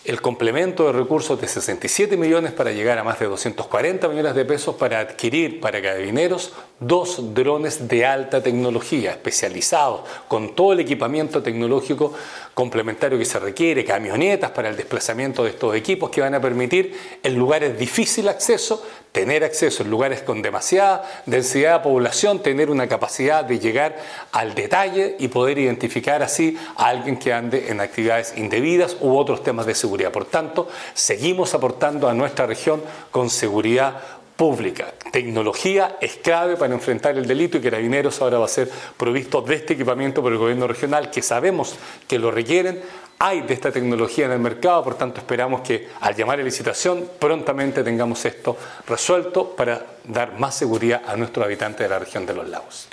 El Gobernador Patricio Vallespin, ratificó el compromiso institucional con la labor policial, esto pese a que, el GORE no tiene facultades directas en el combate a la delincuencia y especificó que se trata del complemento de recursos de 67 millones para llegar a más de 240 millones de pesos para adquirir el equipamiento tecnológico especializado.